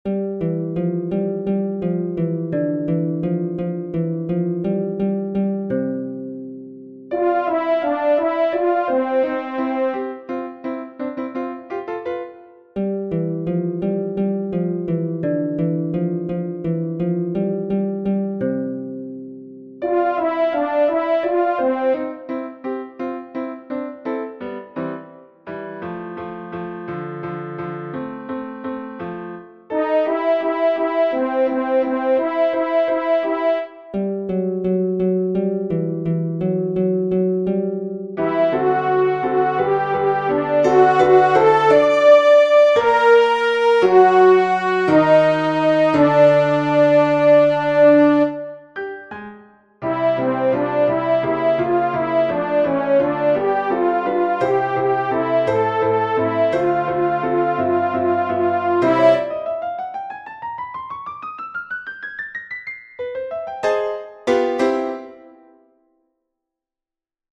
The baritone soloist sounds like a harp. The featured voice is a horn.
ALTO 2